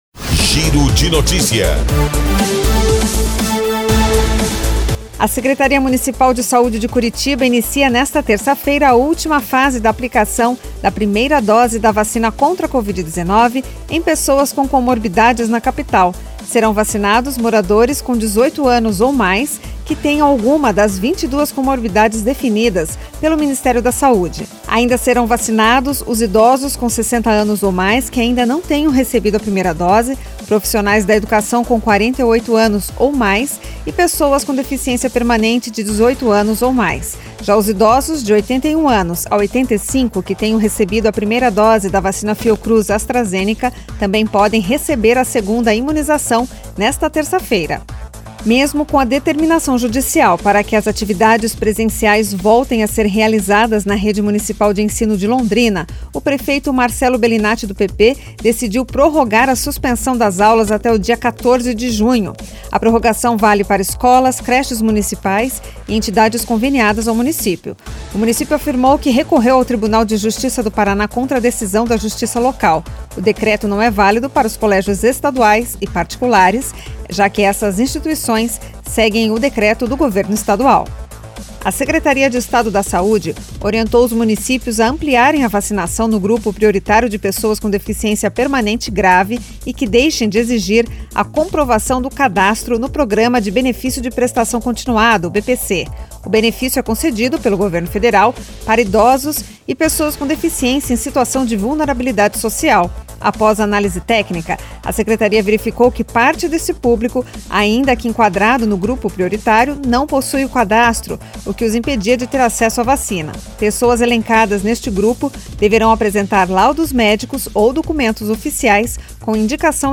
Giro de Notícias Manhã COM TRILHA